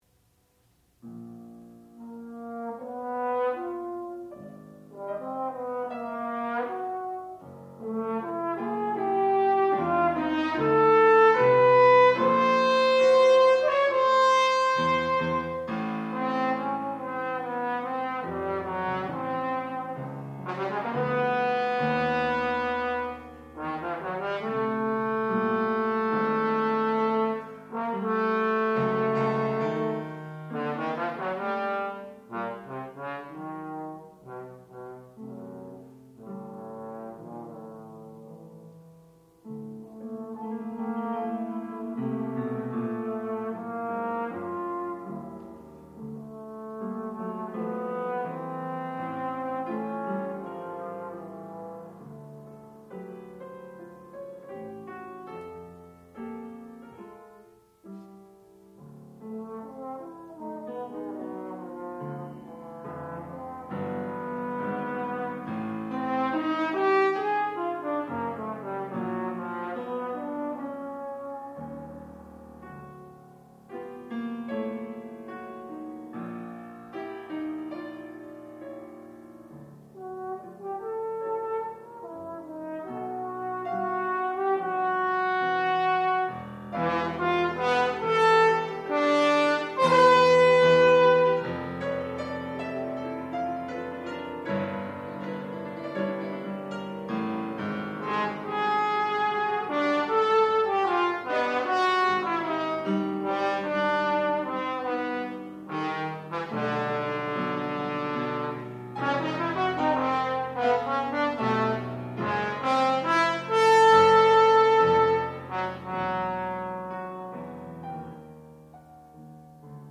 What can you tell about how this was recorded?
Freshman Recital